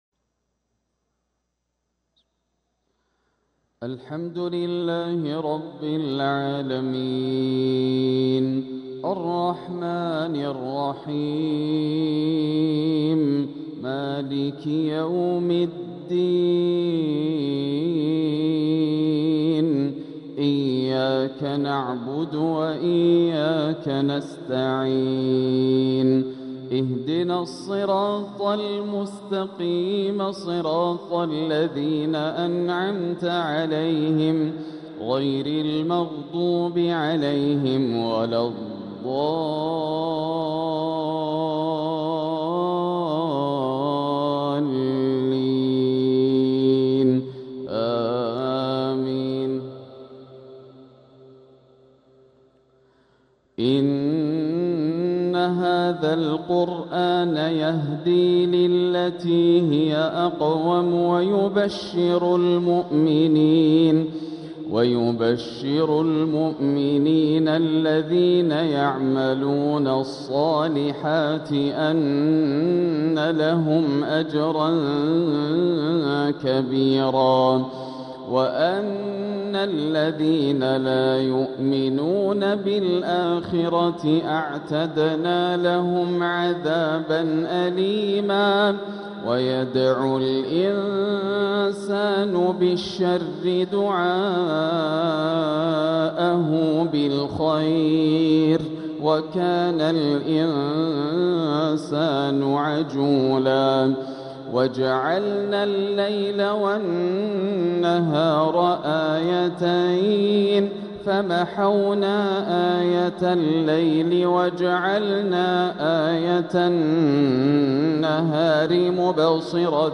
تلاوة من سورة الإسراء 9-24 | عشاء الجمعة 7 صفر 1447هـ > عام 1447 > الفروض - تلاوات ياسر الدوسري